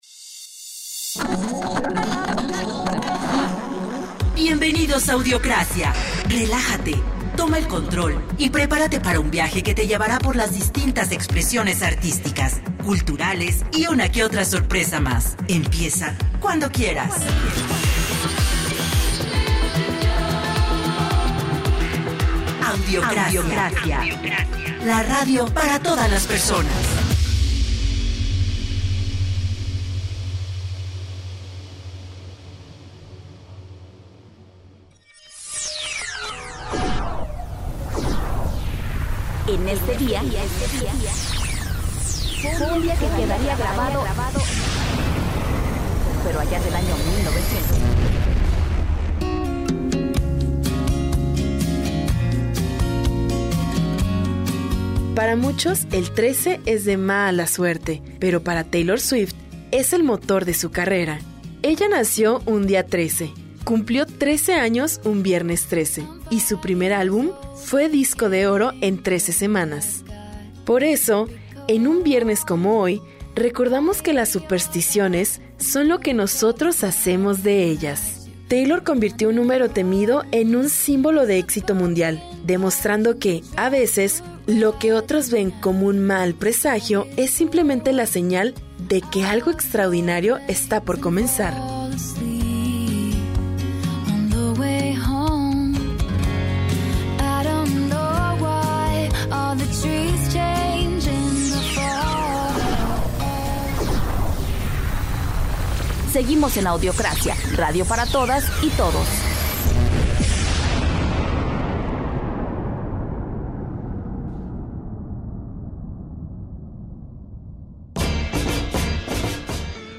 Este viernes Audiocracia llega con música, conversación y buena vibra para cerrar la semana